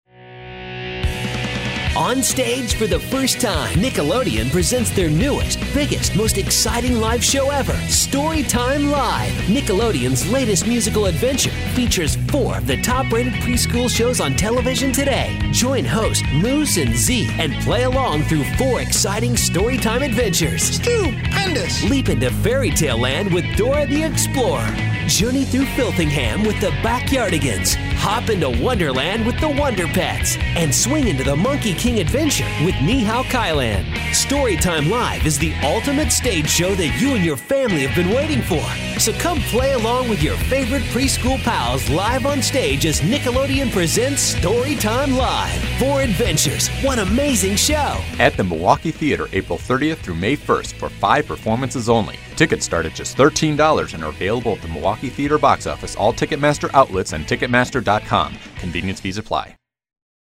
Storytime Live Radio Commercial